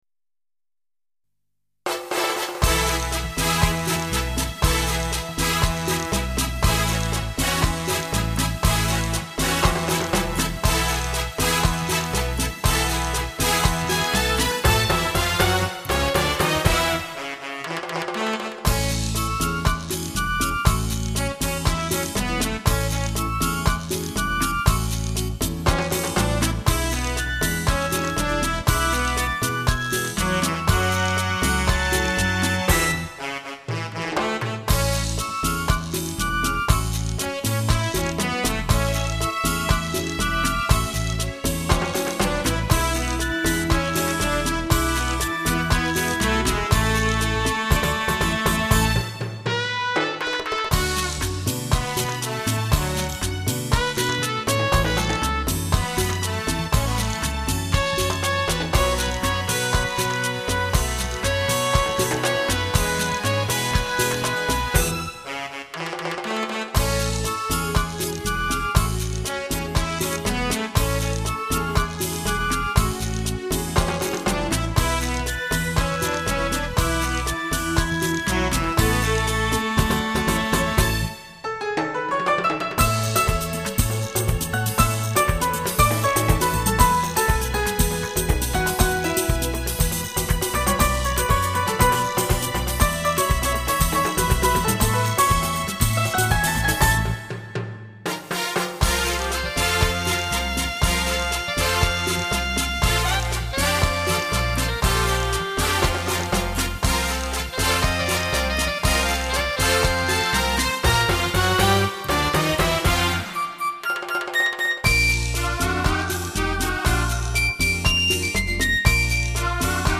20Bit 数码录音